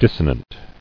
[dis·so·nant]